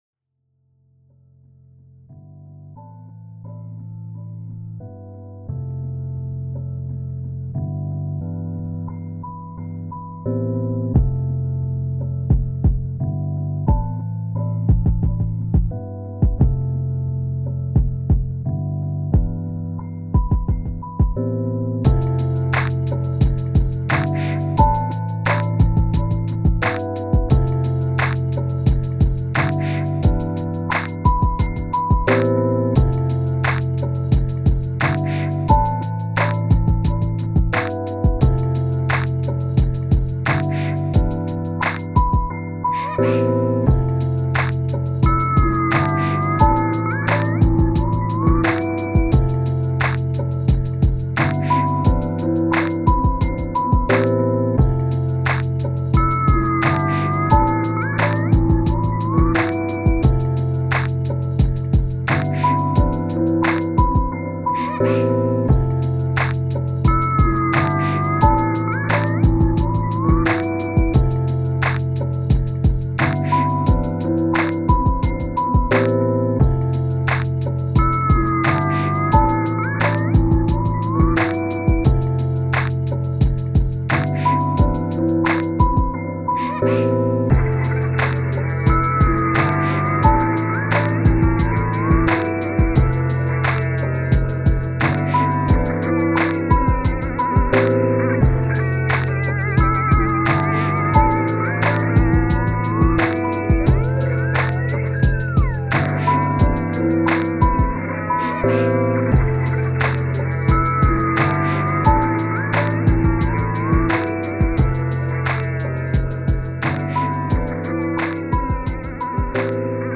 2 Thumb Up 미디어 듣기 어쩌다 보니 묘한 분위기가 되버린 ;;;; 즐감 하세요~ 2 Scrap This!